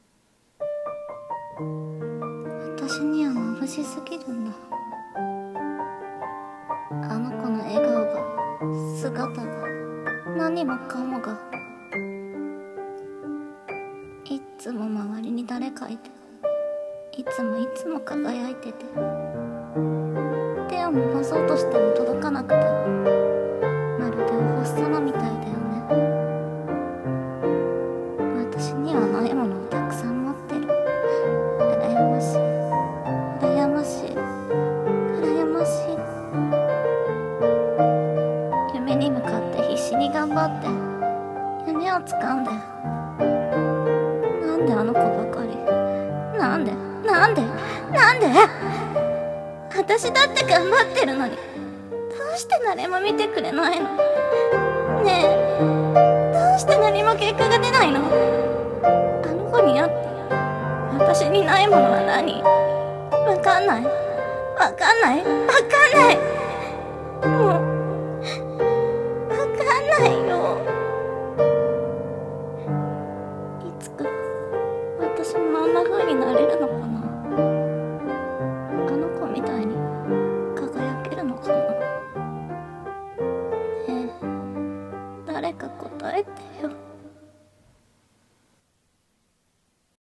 [声劇･朗読]憧れ